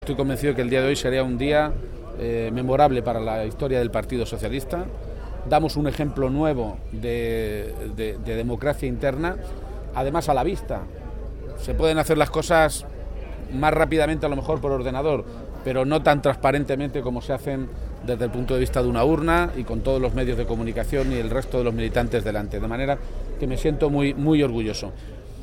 El secretario General del PSOE de Castilla-La Mancha y presidente autonómico, Emiliano García-Page, ha mostrado su satisfacción y su optimismo porque “el proceso de primarias que hoy celebra nuestro partido es un ejemplo de democracia interna” pero sobre todo, “porque es decisivo para el futuro de nuestro país”.
Cortes de audio de la rueda de prensa